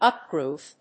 アクセント・音節úp・gròwth